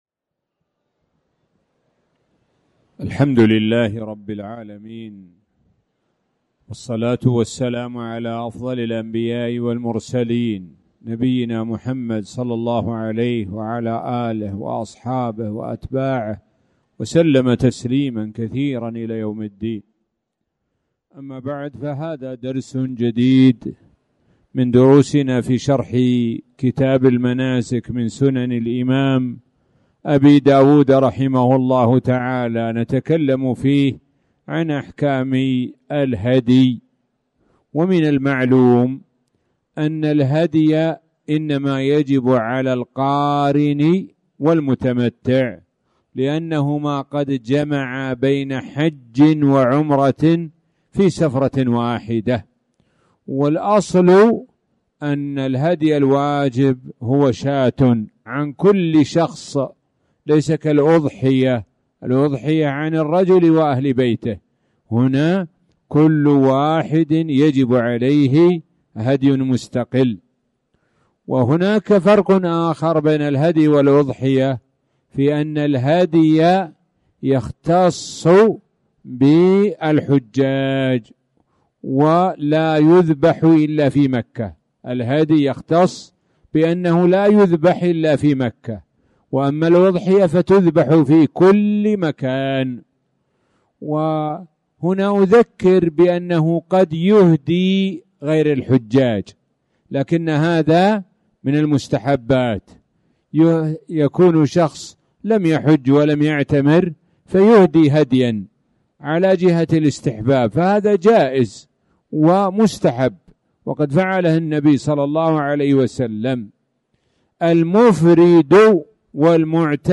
تاريخ النشر ٢١ ذو القعدة ١٤٣٨ هـ المكان: المسجد الحرام الشيخ: معالي الشيخ د. سعد بن ناصر الشثري معالي الشيخ د. سعد بن ناصر الشثري كتب الحج